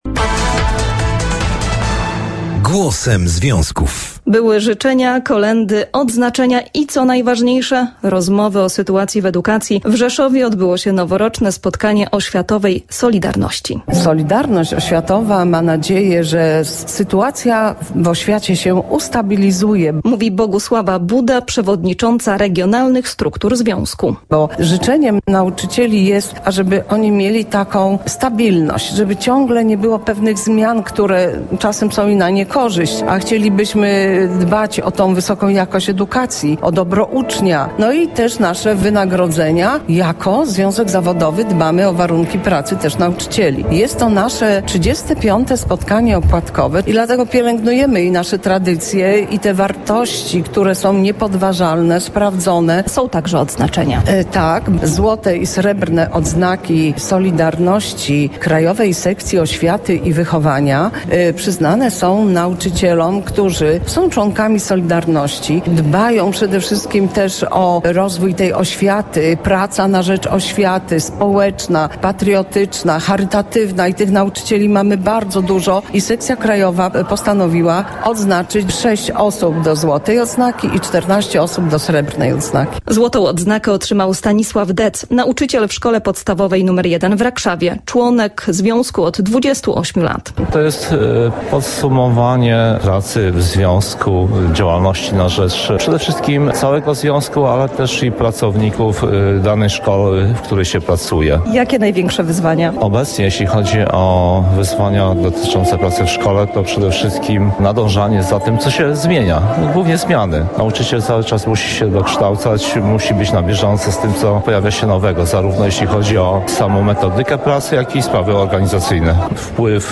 Reportaż w   mp3
35. Spotkanie Opłatkowe  NSZZ „SOLIDARNOŚĆ” Pracowników OŚWIATY I WYCHOWANIA W RZESZOWIE